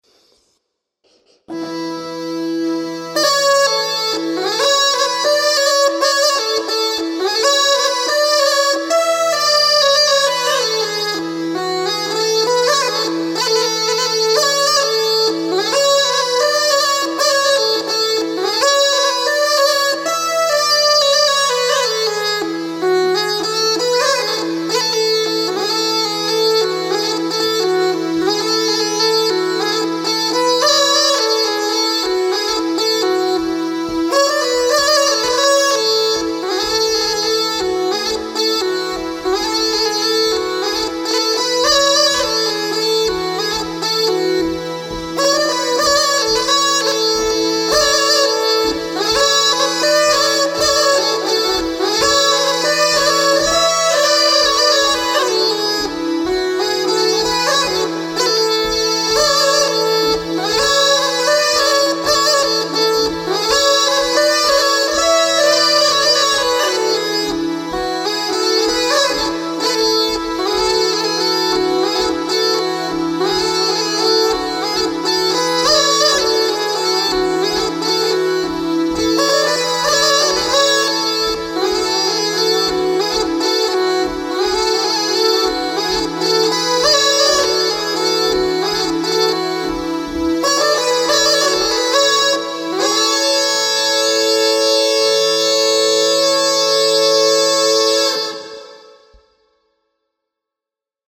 Repar med säckpipan inför Oktoberstämman den 29/10 i Uppsala
Polska efter Erik August Sellin Tysslinge “Hins polska” Spelas A-dur